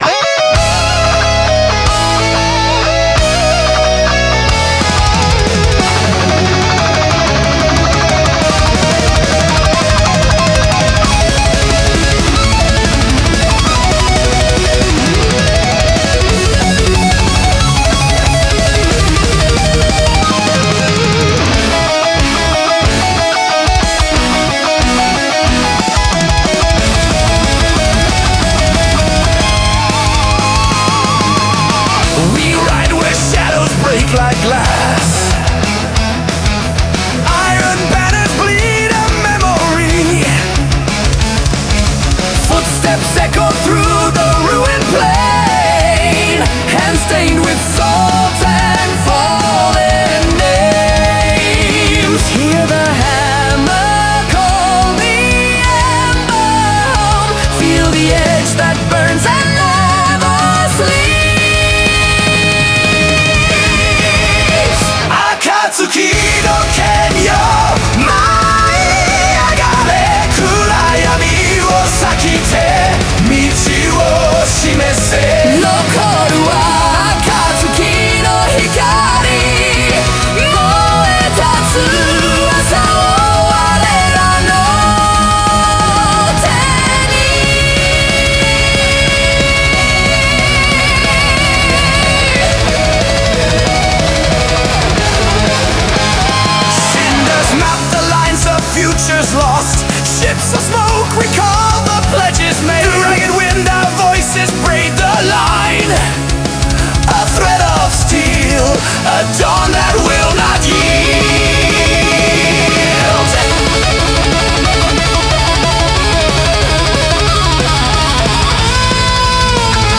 Melodic Power Metal
Add orchestral hit in chorus for extra lift.